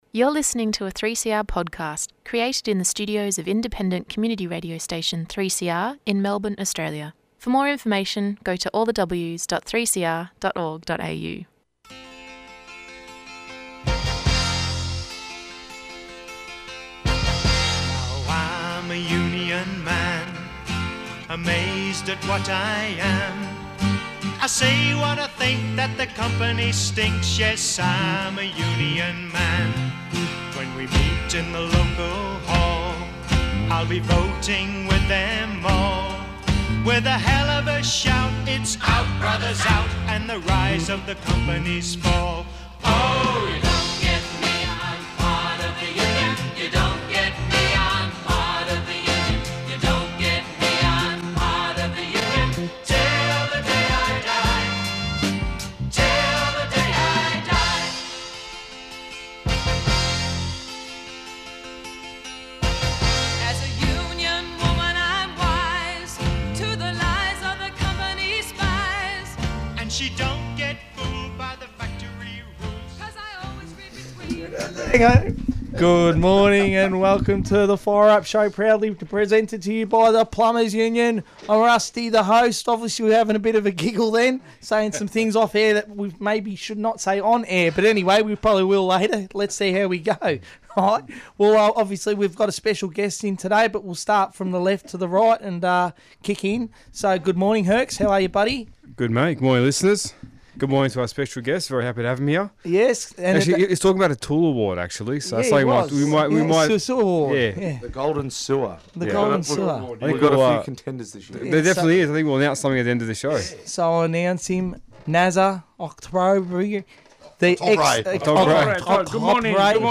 A fortnightly show presented by members of the PTEU (Plumbing and Pipe Trades Employess Union) looking at the issues affecting workers in the plumbing and pipe trades. They take an irreverent approach to the current day issues, including, Health and Safety, workplace unionism, work-site conditions and the politics of employment.